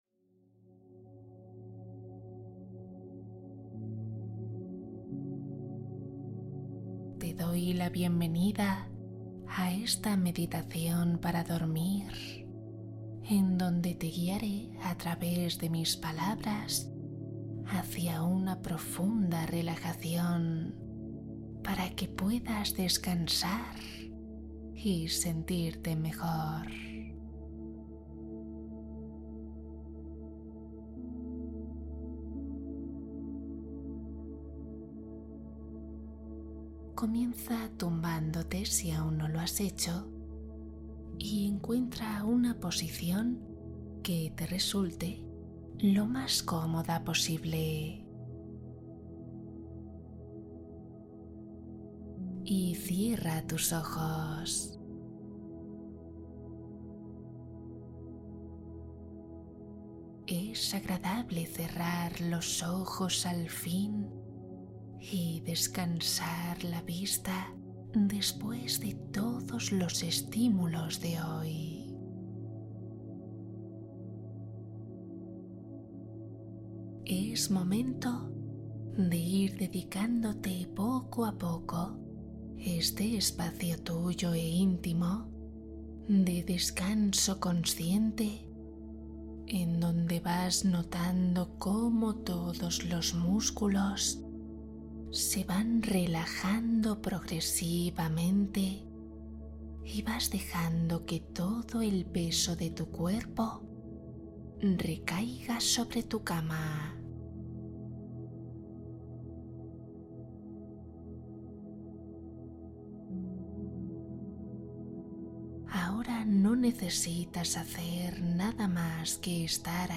¿TIENES INSOMNIO? Meditación guiada para dormir profundamente y relajarte